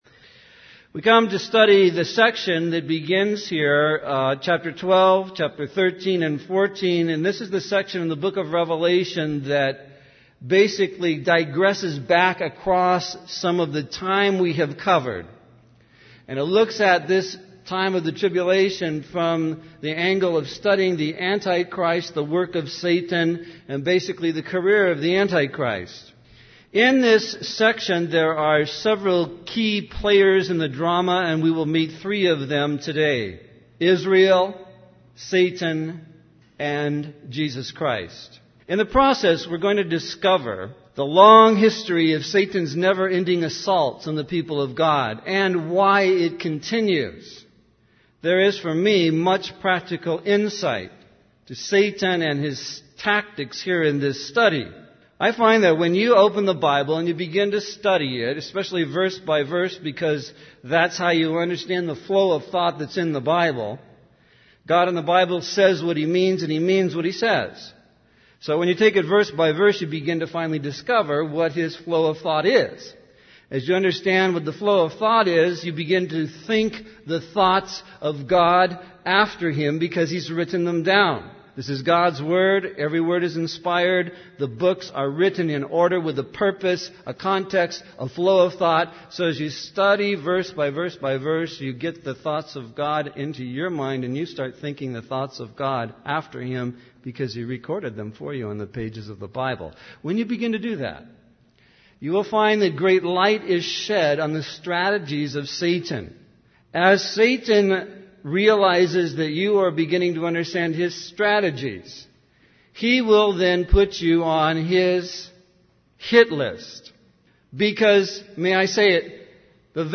In this sermon, the preacher discusses the book of Revelation and its significance in understanding God's goodness and love. He emphasizes that God runs to forgive when we come to him for forgiveness.